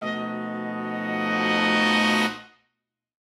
Index of /musicradar/gangster-sting-samples/Chord Hits/Horn Swells
GS_HornSwell-F7b2b5.wav